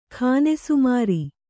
♪ khānesumāri